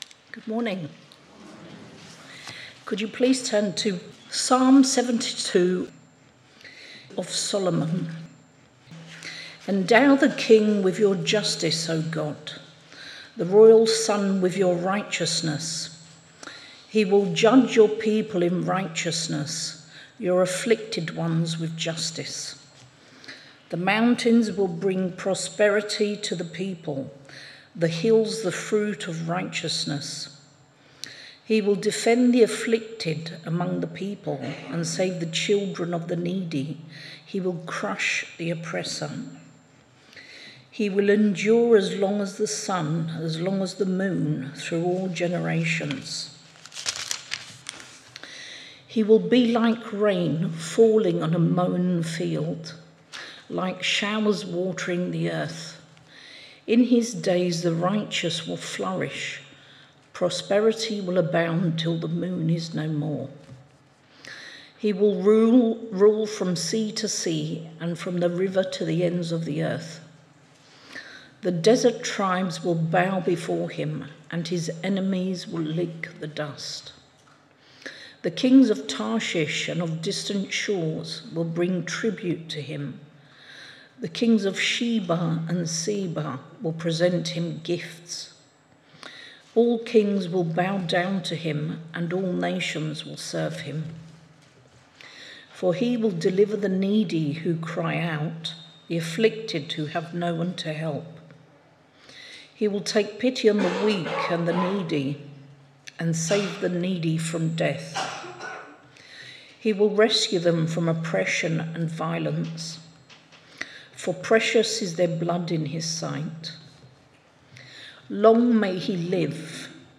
Media for Sunday Service on Sun 10th Aug 2025 10:00
Passage: Psalm 72, Series: Summer of Psalms Theme: Sermon